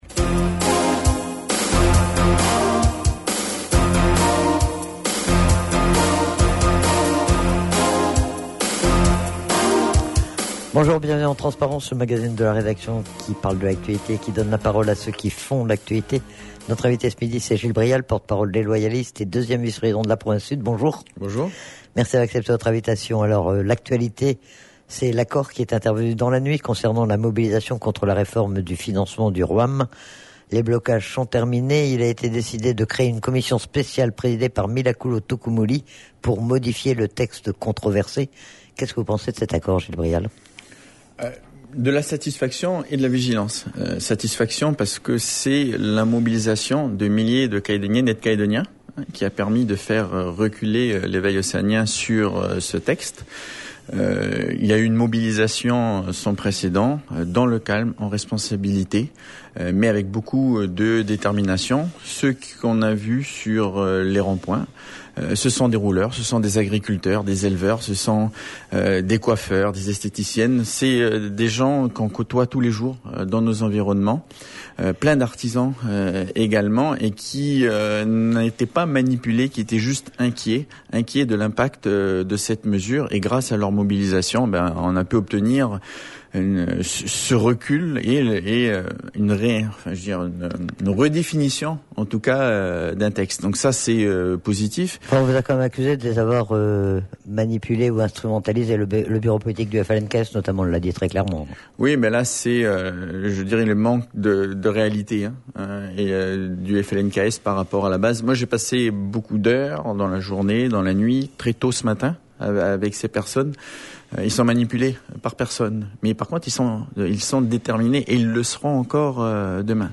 Gil Brial, porte-parole des Loyalistes et 2ème vice-président de la province Sud.